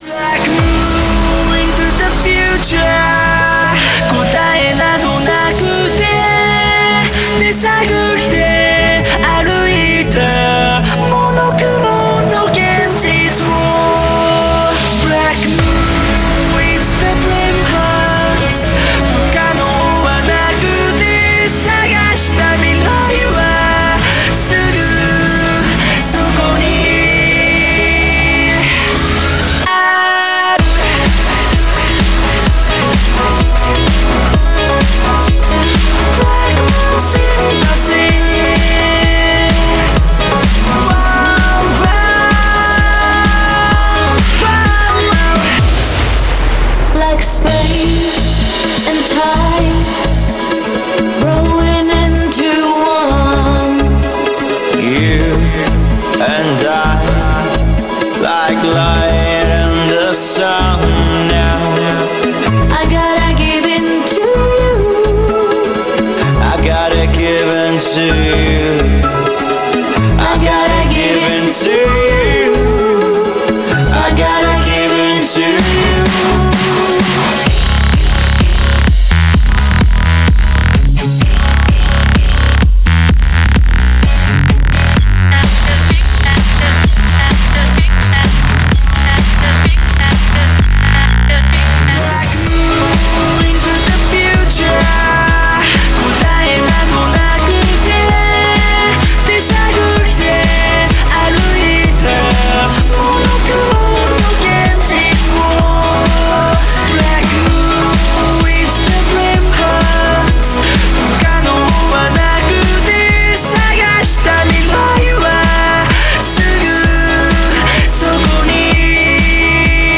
BPM64-128
Audio QualityMusic Cut